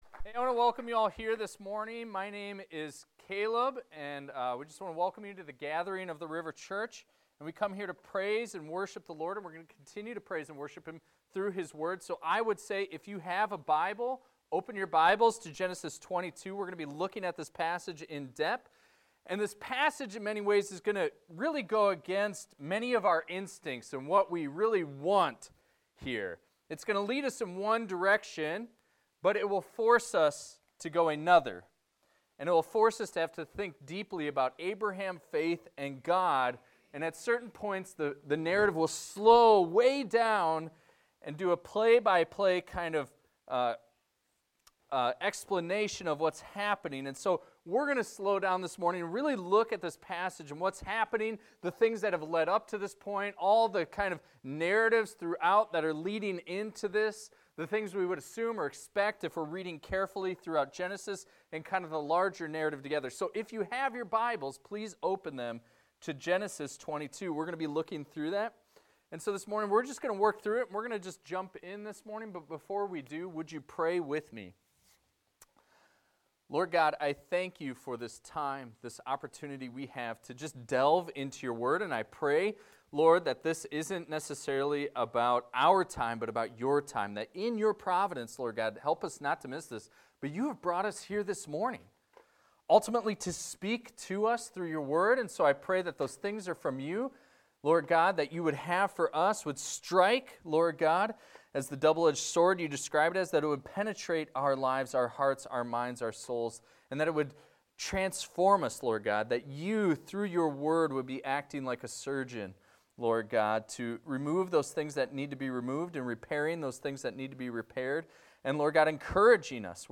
This is a recording of a sermon titled, "The Father's Sacrifice ."